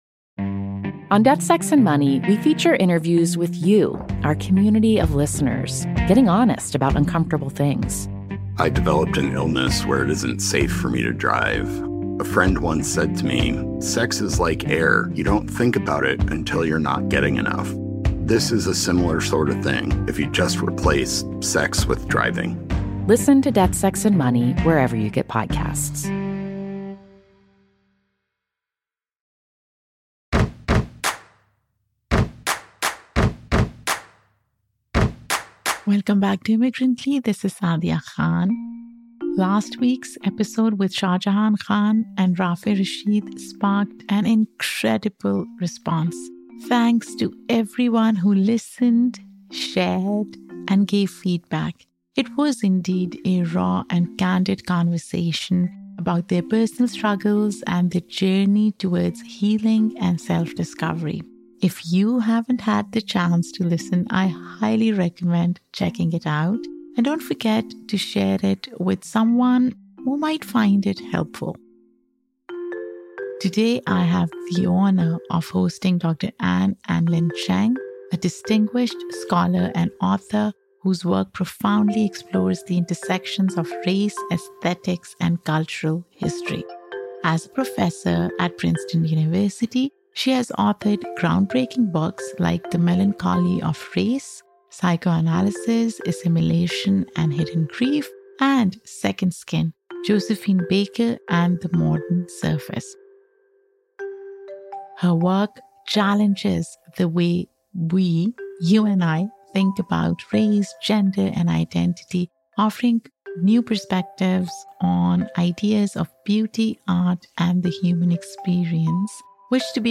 It's a transformative conversation that invites you to see the world—and your place in it—in a new light.